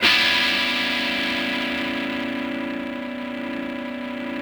ChordC#m7.wav